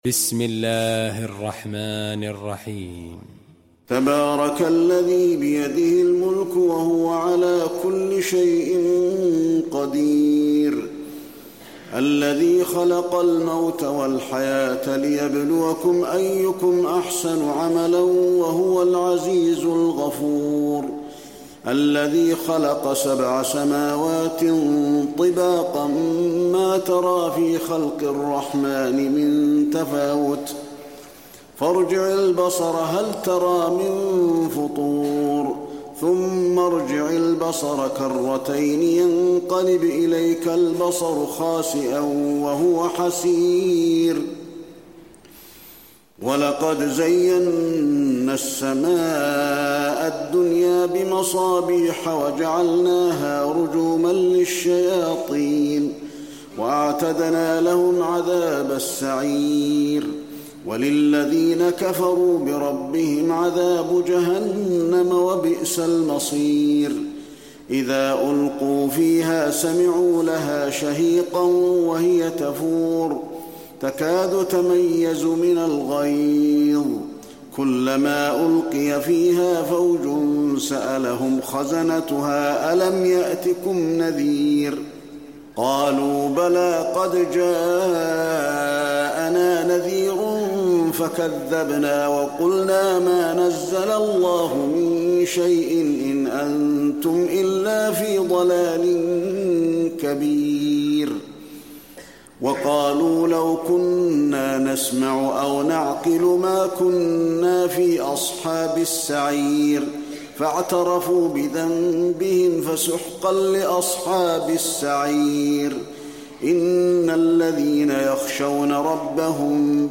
المكان: المسجد النبوي الملك